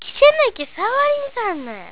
ためになる広島の方言辞典 か．